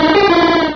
Cri de Simularbre dans Pokémon Rubis et Saphir.